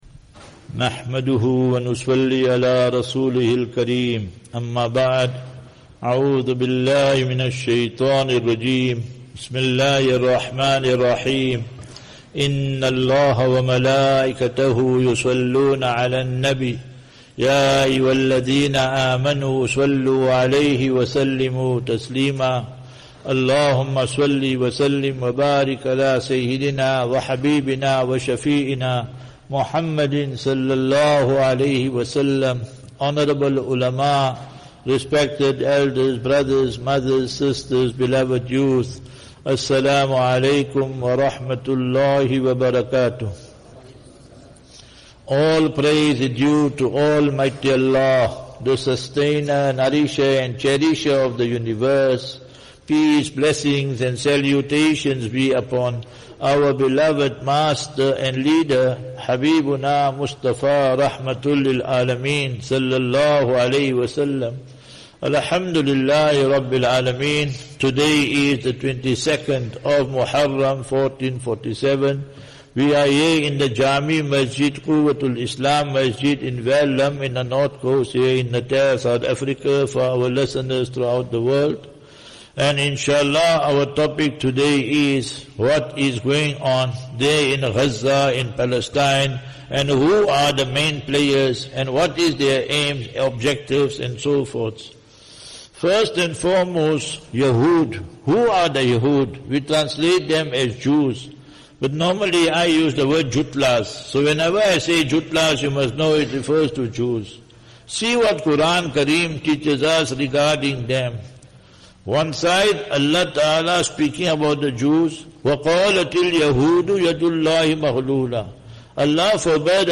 18 Jul 18 July 25 - Jumu,ah Lecture at Quwwatul Islam Jaame Musjid (VERULAM) DBN.